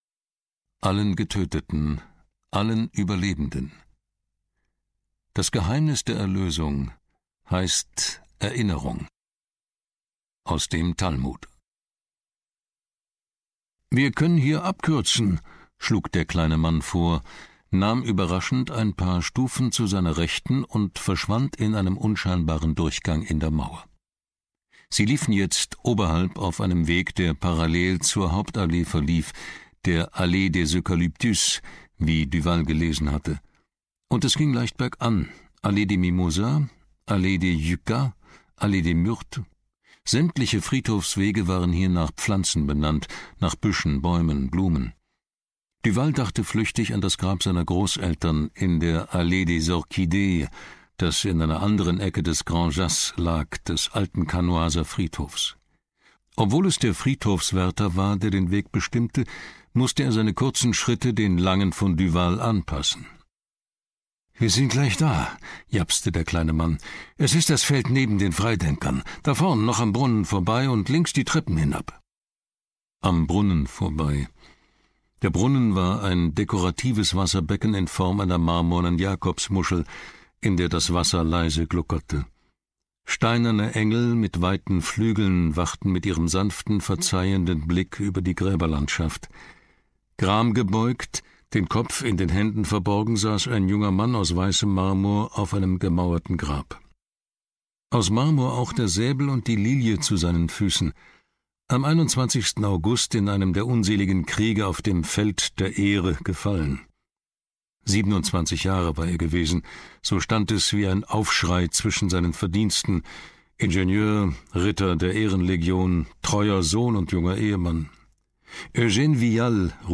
Gert Heidenreich (Sprecher)
2021 | Ungekürzte Lesung
Gert Heidenreich ist ein deutscher Schriftsteller, Journalist, Rundfunk- und Hörbuchsprecher.